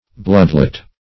Search Result for " bloodlet" : The Collaborative International Dictionary of English v.0.48: Bloodlet \Blood"let`\ (bl[u^]d"l[e^]t`), v. t. [AS. bl[=o]dl[=ae]tan; bl[=o]d blood + l[=ae]atan to let.] bleed; to let blood.
bloodlet.mp3